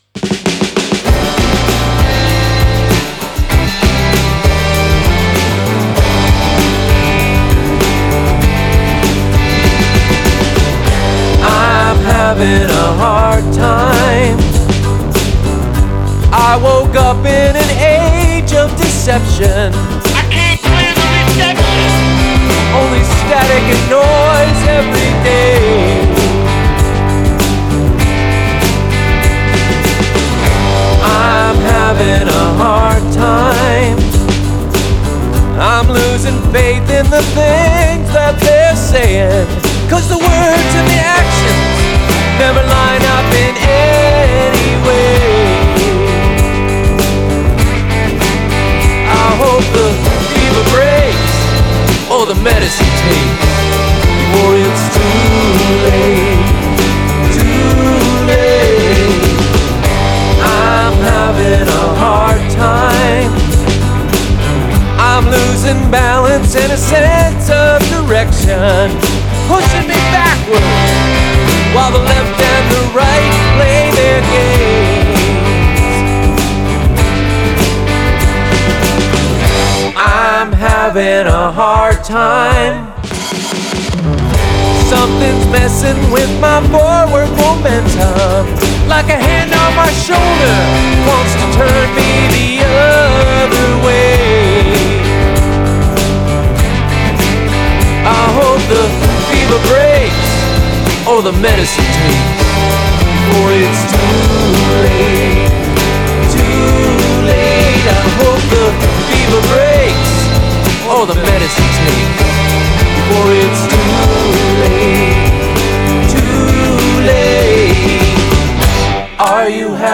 This is an original tune recorded in Ardour 8.2.5 and mixed in Mixbus 10 Pro.
The Blonde Bop (multitrack export from Hydrogen) was used and Loomer Aspect for the synth basses, an acoustic guitar and everything else is Fender telecasters in various tunings.